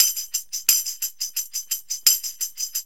TAMB LP 86.wav